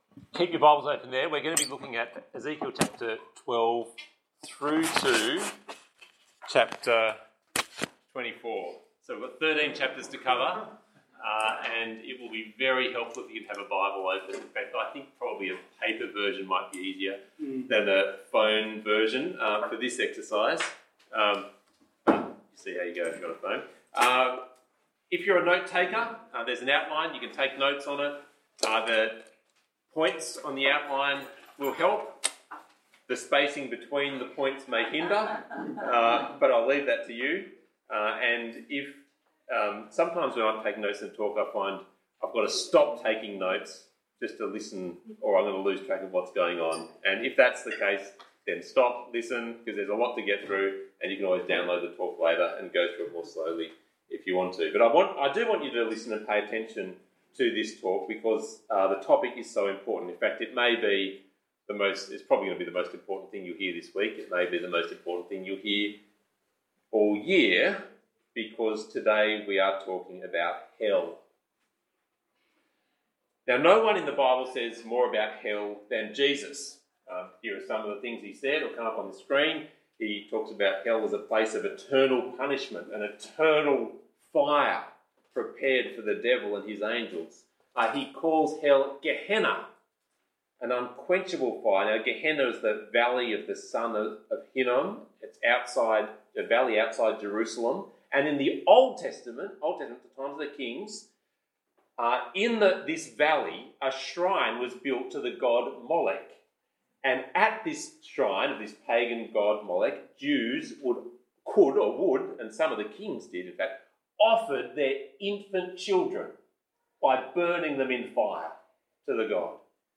Ezekiel 12:1-24:27 Talk Type: Bible Talk « Ezekiel Talk 3